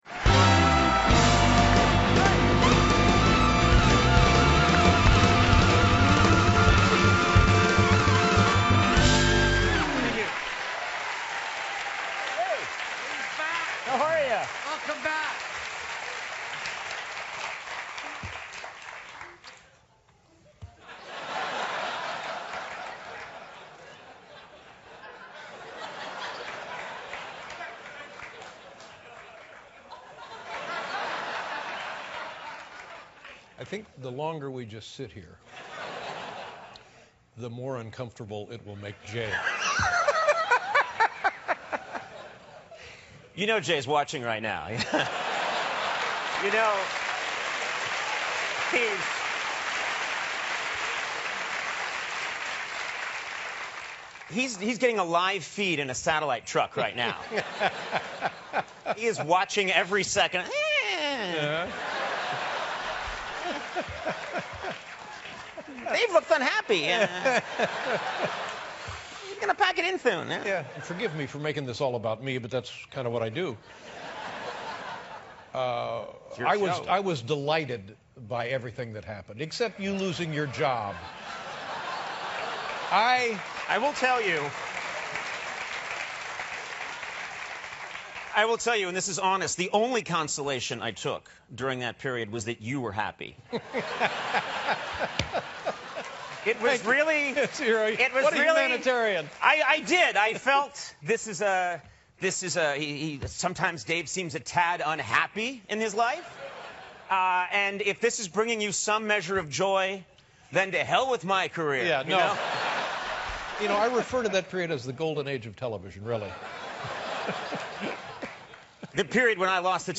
访谈录 2012-05-21&05-23 脱口秀节目主持康纳.欧布莱恩专访 听力文件下载—在线英语听力室